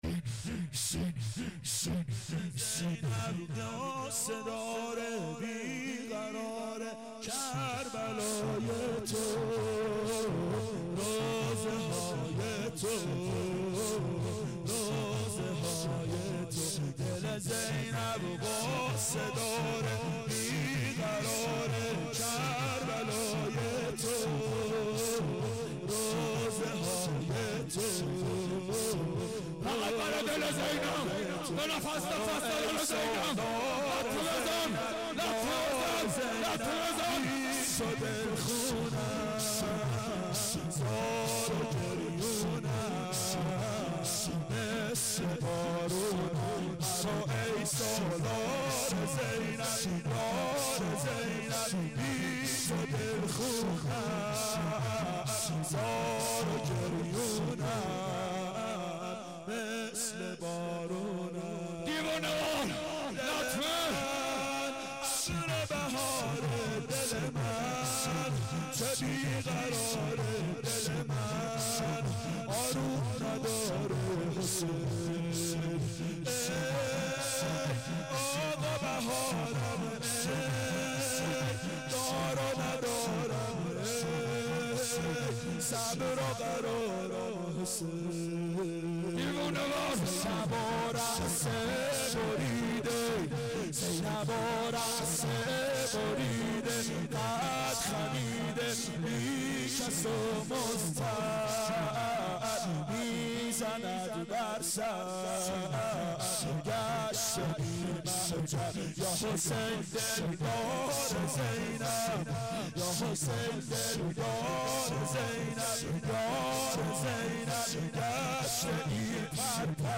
اربعین 91 هیئت متوسلین به امیرالمؤمنین حضرت علی علیه السلام